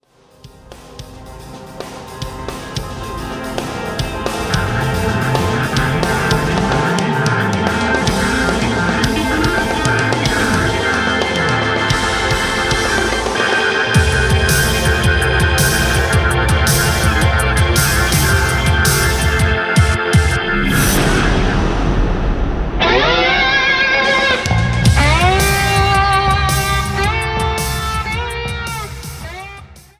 ジャンル Progressive
アンビエント
インストゥルメンタル
シンフォニック系
多重録音